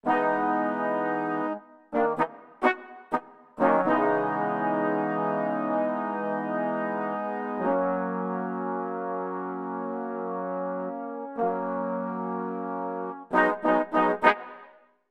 14 brass 2 A1.wav